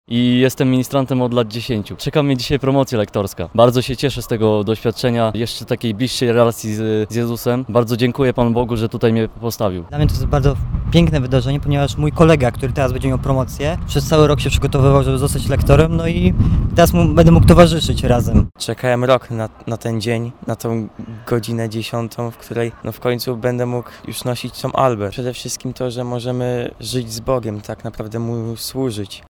O odczucia kandydatów przed Eucharystią zapytał nasz reporter.